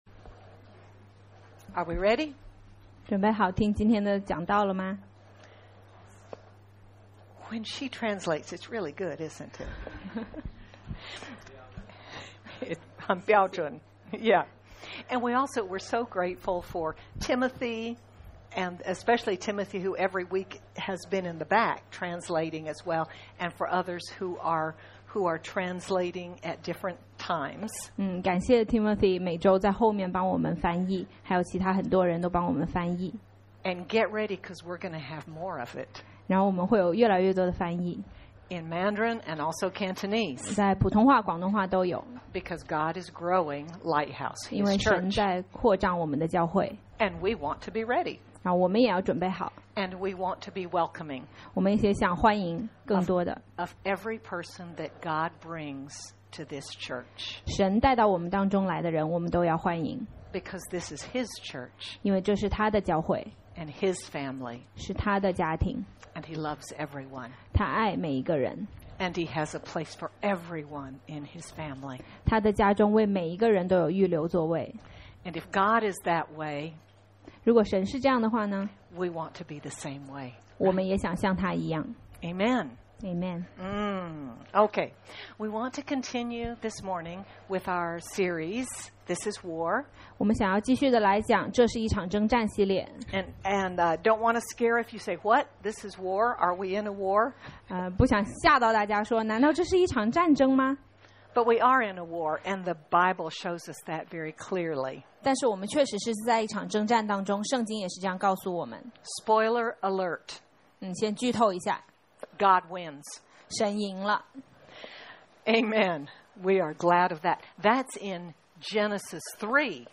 Aug 25, 2025 Be Alert MP3 SUBSCRIBE on iTunes(Podcast) Notes Discussion Sermons in this Series Continuing from last week’s message, Be Aware, how must we respond in spiritual warfare? We must take the knowledge the Spirit gives us through the Word and turn it into action! Sermon by